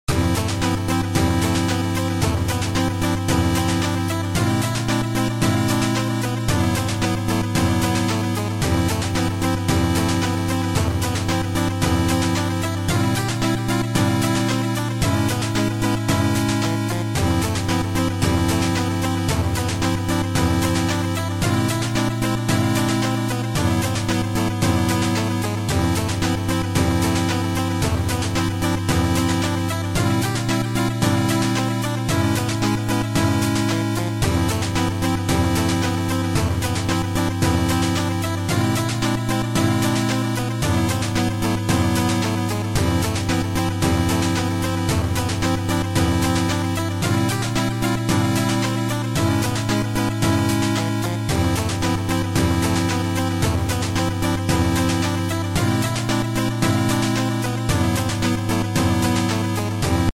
Video Game soundtracks